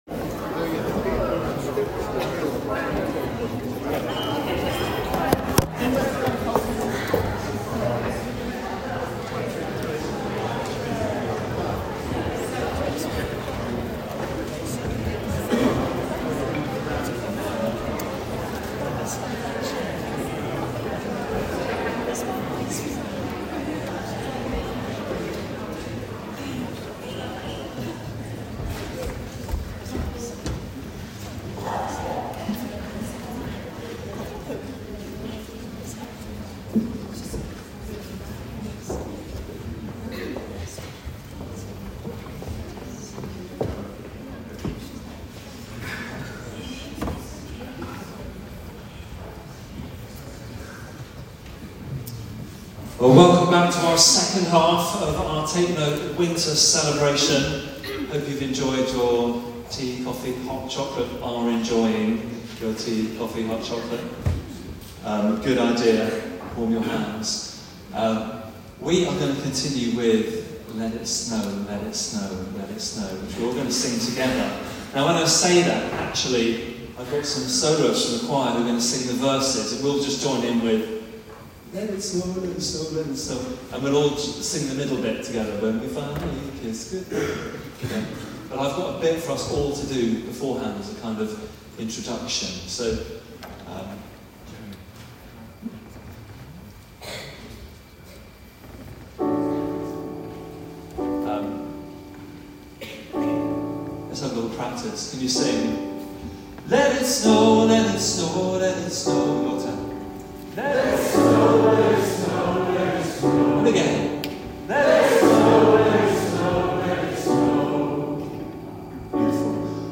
Take note concert part 2